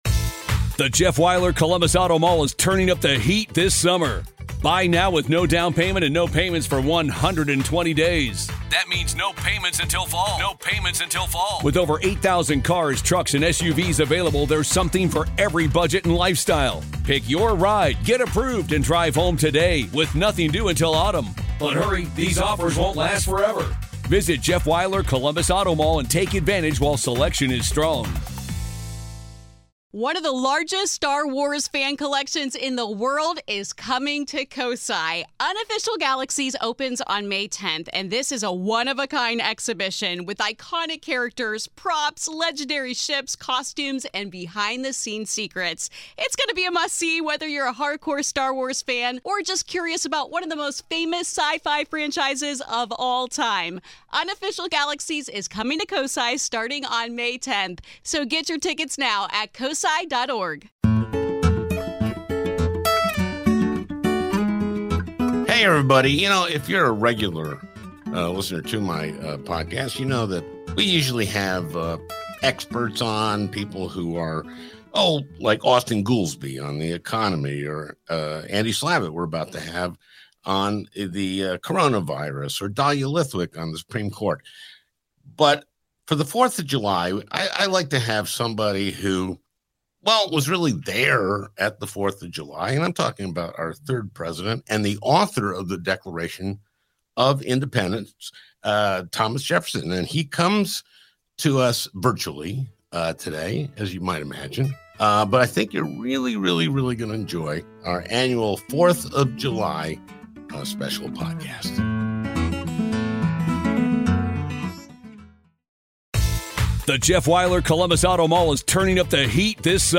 Al interviews our third President on the 4th of July.